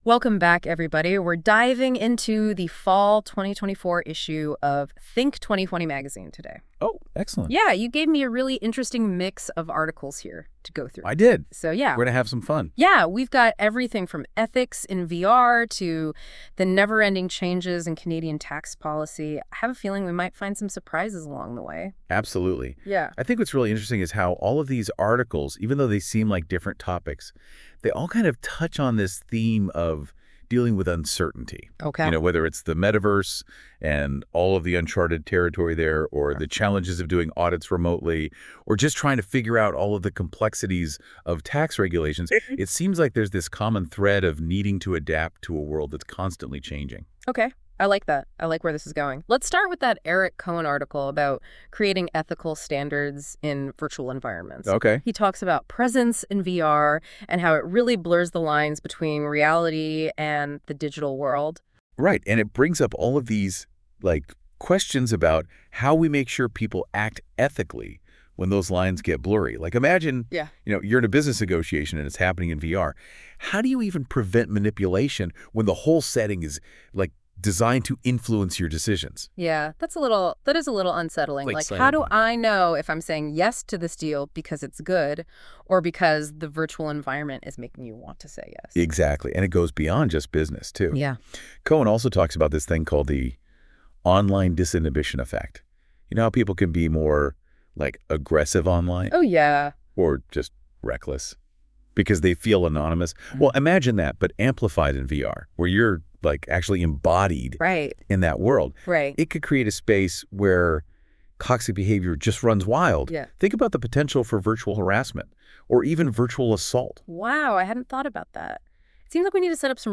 These podcasts were developed using NotebookLM, a product of Google. Compete accuracy cannot be assumed, however the editors feel the podcasts are an entertaining and informative means of gaining insight into the published contents.